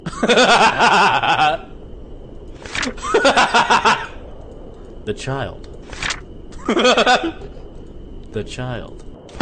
Scary Laugh Sound Effect Free Download
Scary Laugh